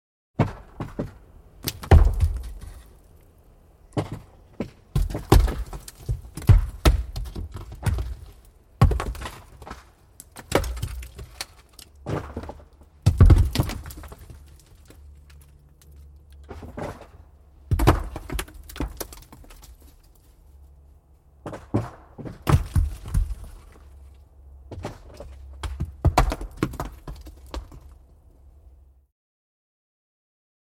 На этой странице собраны звуки камнепада — от легкого шелеста скатывающихся камешков до грохота крупных обвалов.
Большой камень катится вниз